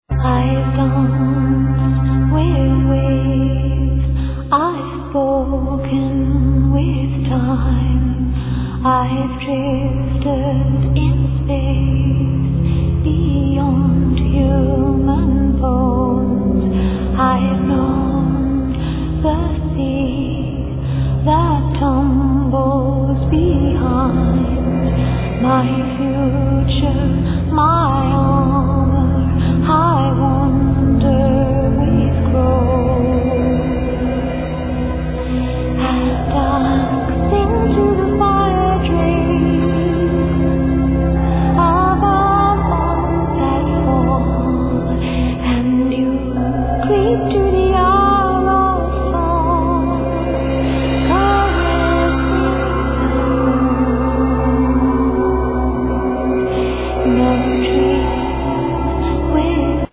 Vocals,Rainstick, Mandlin, Bells, Windchime, Ocarina, Shells
Narnian horn, Cymbals, Paper drum, Keyboards